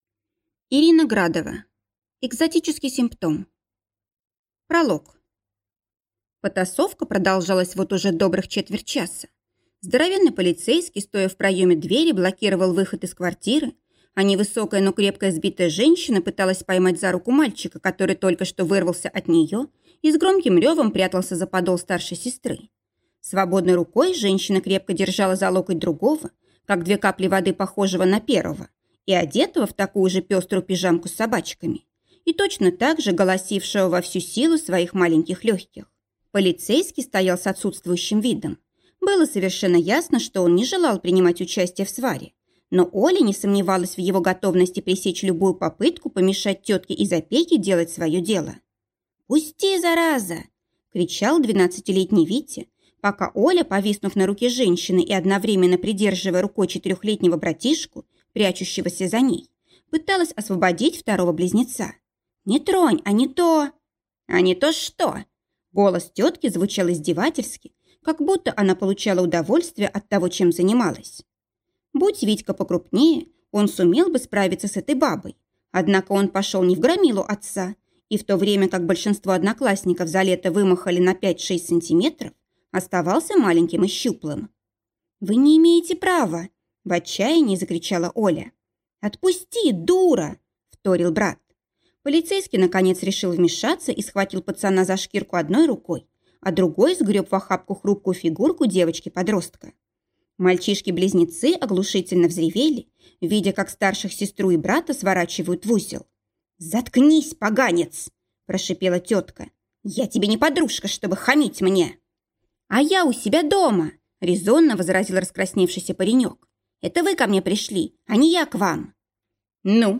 Аудиокнига Экзотический симптом | Библиотека аудиокниг